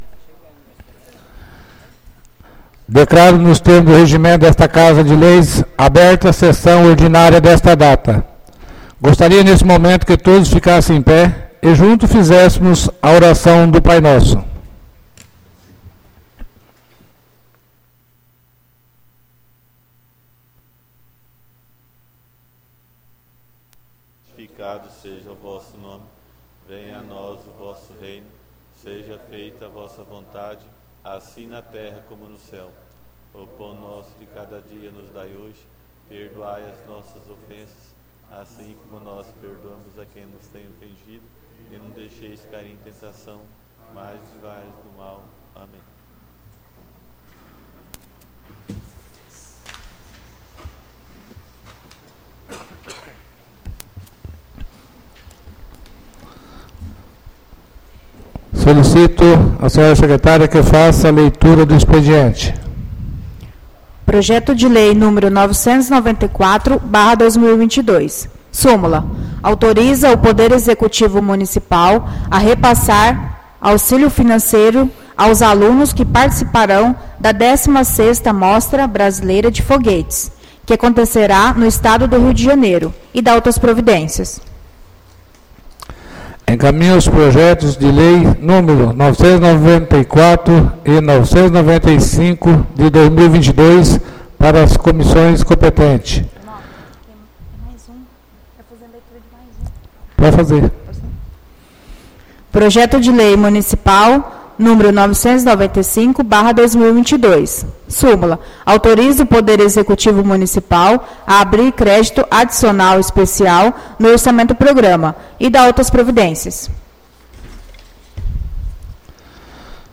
ÁUDIO SESSÃO 05-09-22 — CÂMARA MUNICIPAL DE NOVA SANTA HELENA - MT